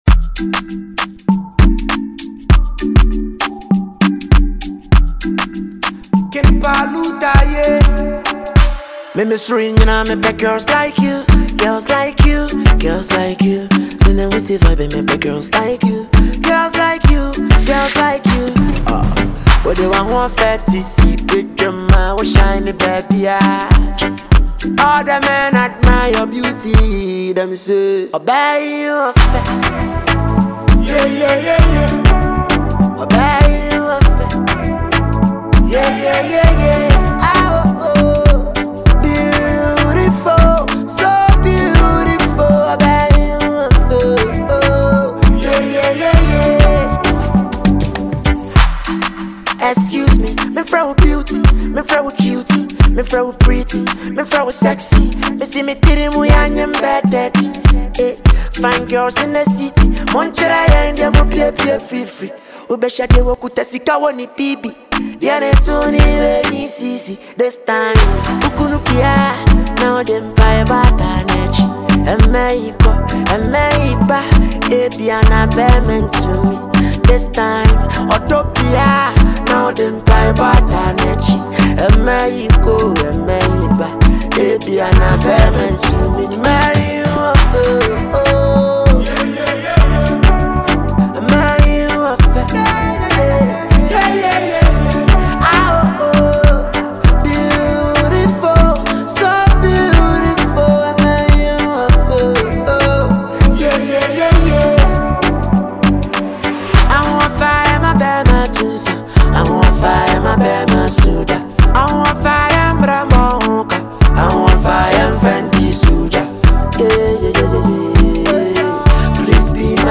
a versatile singer and rapper
a solo track